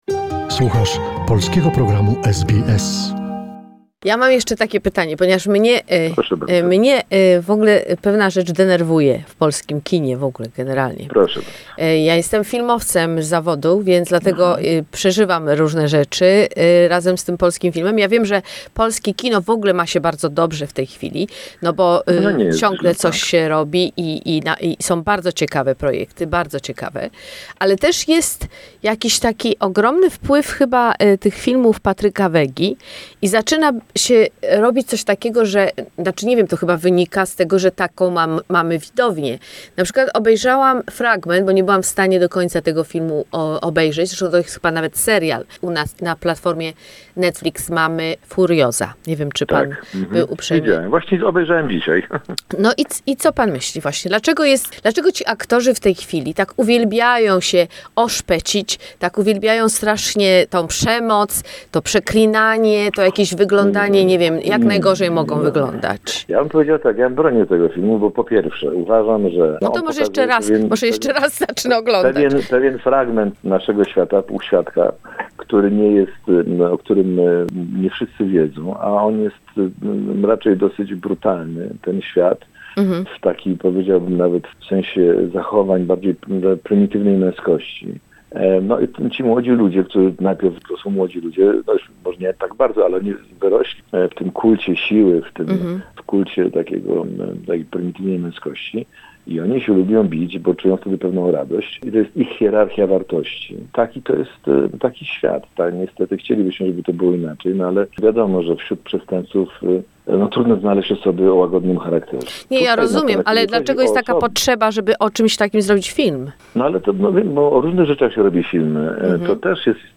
Interview with Wojciech Adamczyk, the creator of the cult series "Rancho" - part 2